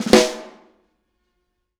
R_B Snare Roll - Close.wav